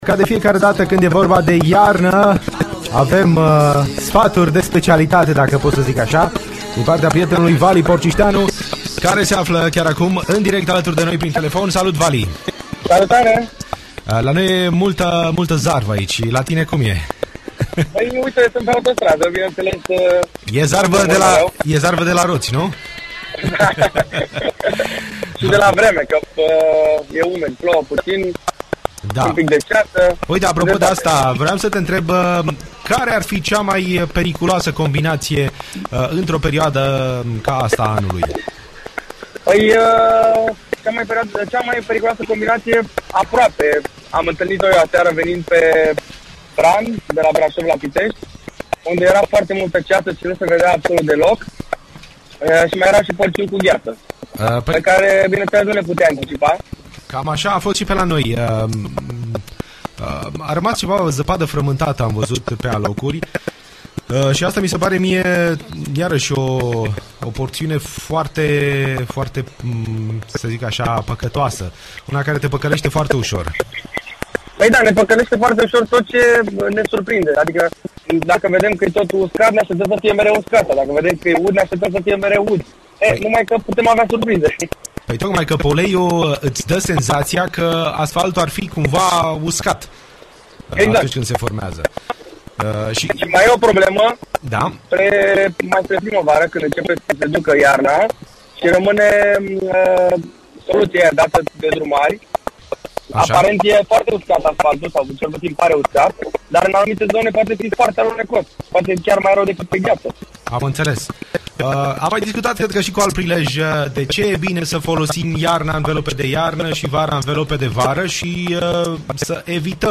Ascultă dialogul live.